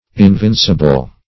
Invincible \In*vin"ci*ble\, a. [L. invincibilis: cf. F.
invincible.mp3